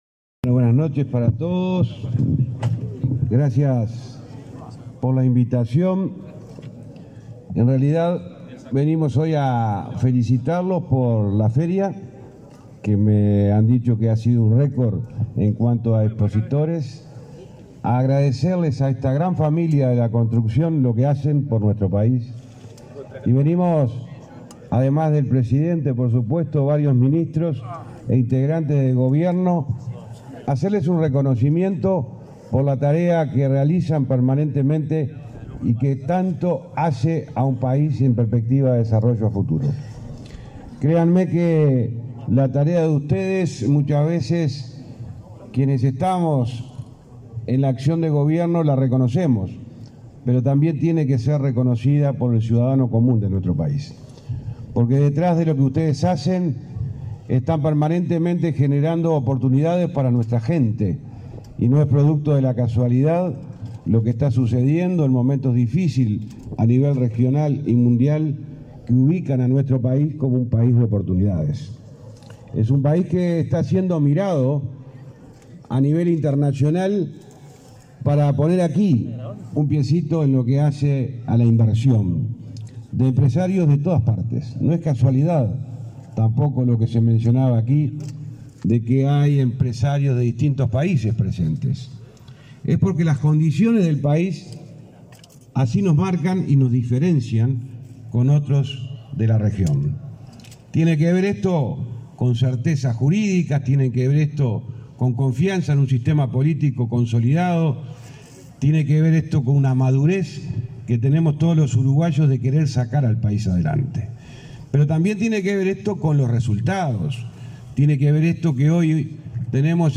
Palabras del ministro de Transporte y Obras Públicas, José Luis Falero
Palabras del ministro de Transporte y Obras Públicas, José Luis Falero 19/10/2023 Compartir Facebook X Copiar enlace WhatsApp LinkedIn Con la presencia del presidente de la República, Luis Lacalle Pou, se inauguró, este 18 de octubre, la Feria de la Construcción. En el evento disertó el ministro de Transporte y Obras Públicas, José Luis Falero.